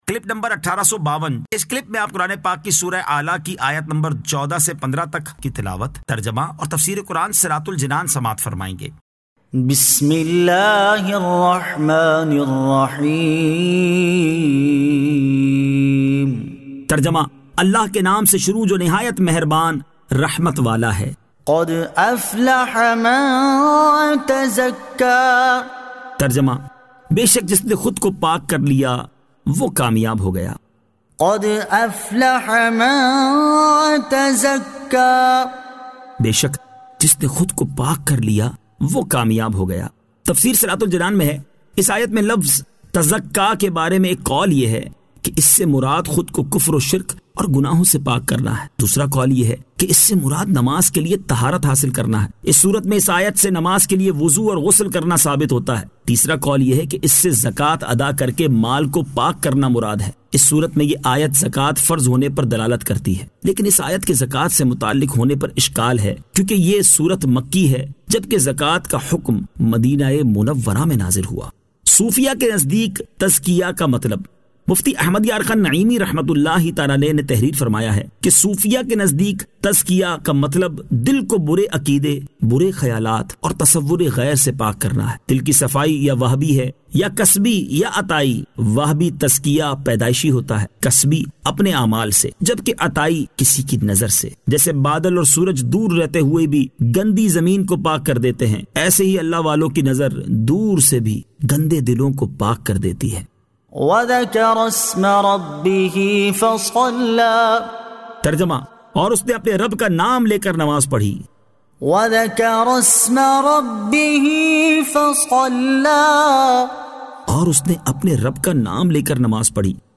Surah Al-A'la 14 To 15 Tilawat , Tarjama , Tafseer